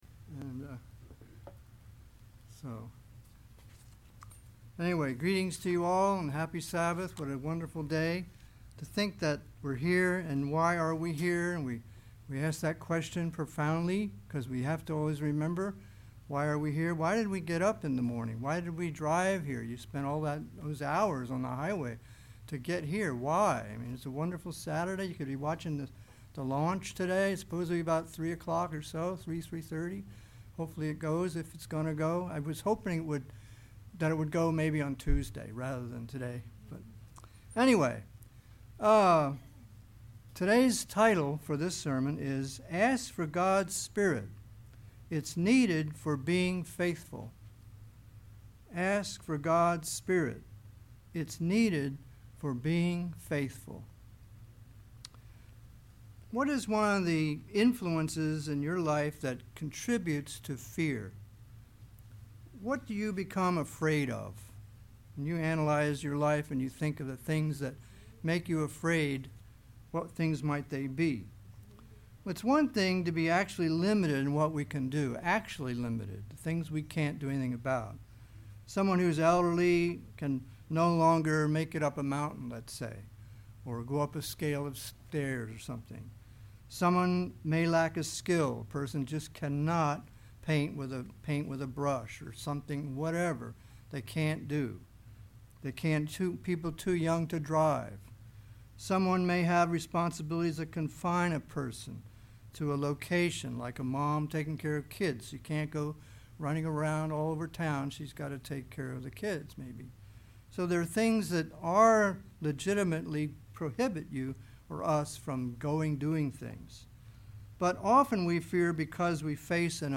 Given in Ft. Myers, FL Ocala, FL Vero Beach, FL